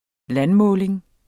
Udtale [ -ˌmɔːleŋ ]